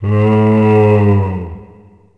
c_zombim2_atk3.wav